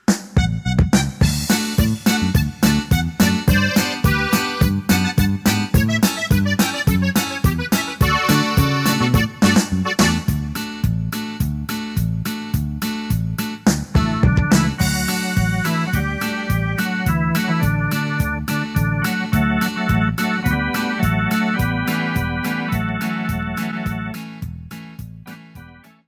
44-Norteno-1.mp3